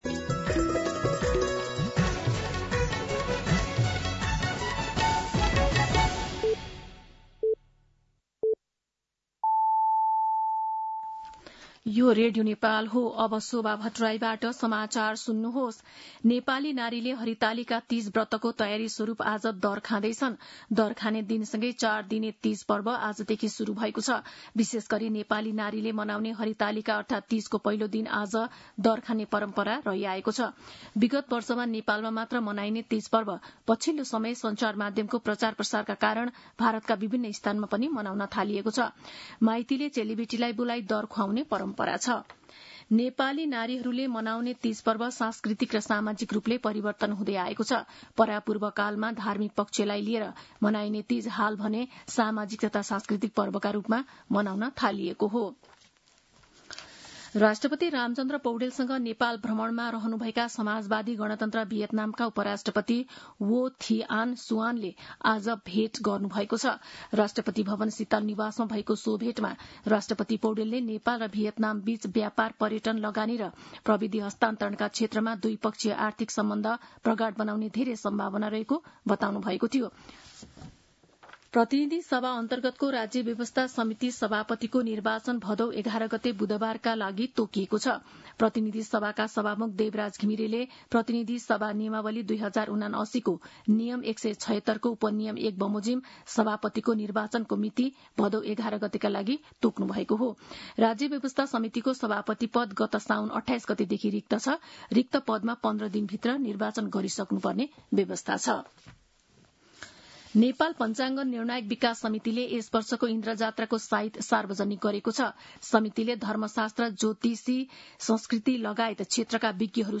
दिउँसो ४ बजेको नेपाली समाचार : ९ भदौ , २०८२
4-pm-News-09.mp3